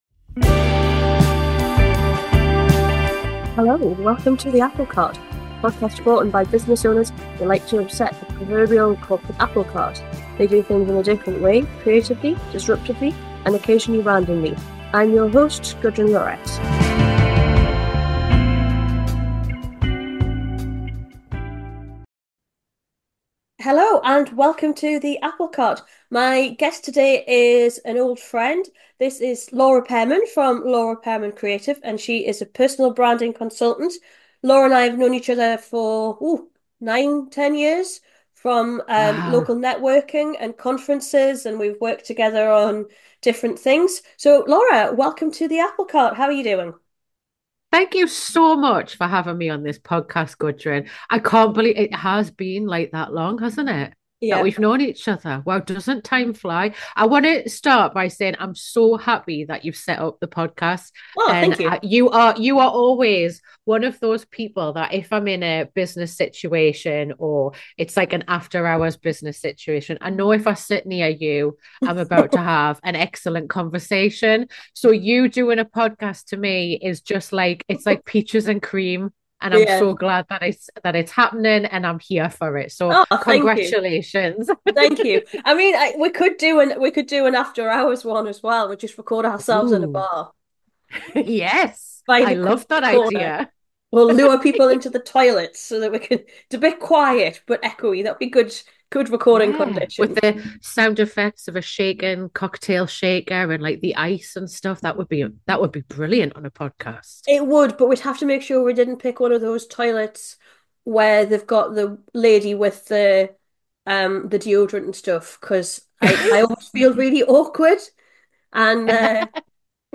This is a wide-ranging and candid chat between two old friends.